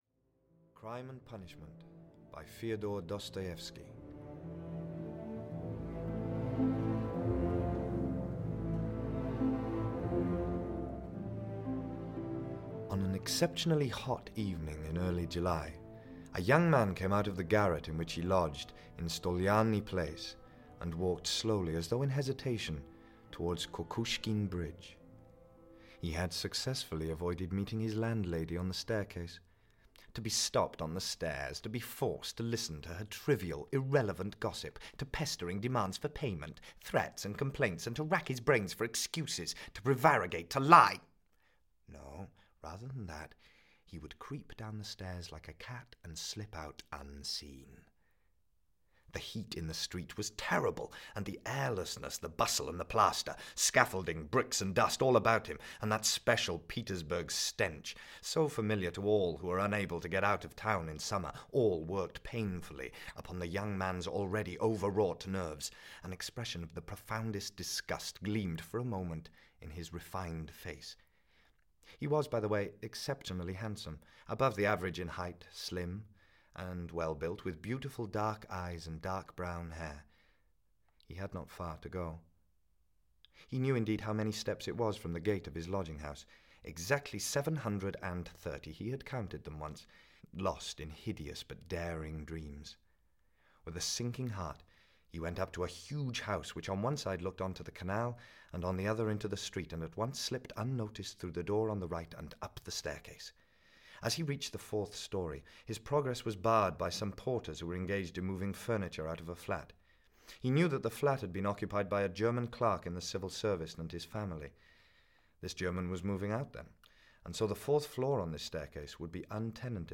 Audio kniha
• InterpretMichael Sheen